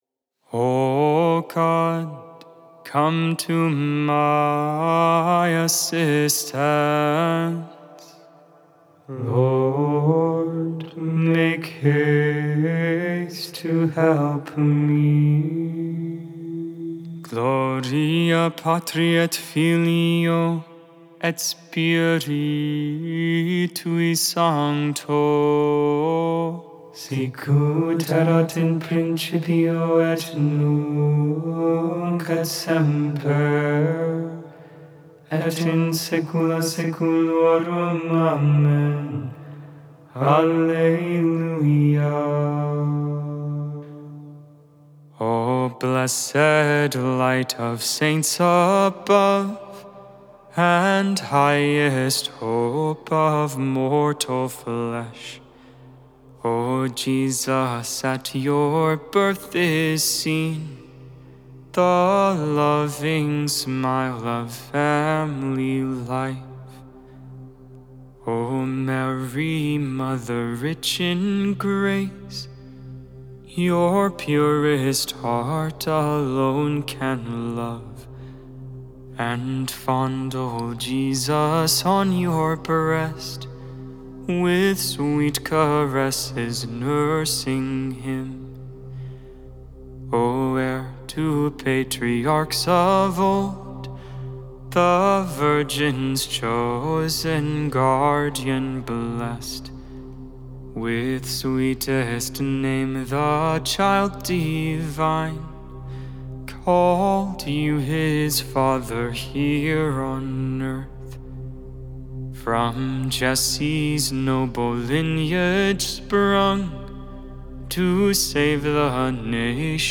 Vespers II, Sunday Evening Prayer